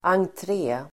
Uttal: [angtr'e:]